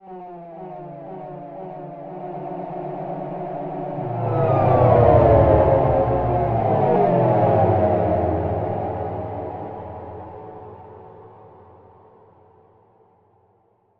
Descarga de Sonidos mp3 Gratis: sintetizador 17.
transformado-sintetizador-sonidos_1.mp3